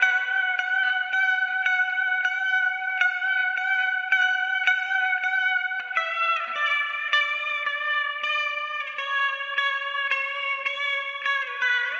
Index of /DESN275/loops/Loop Set - Guitar Hypnosis - Dream Pop Guitar
CelebrationCake_80_D_GuitarLead.wav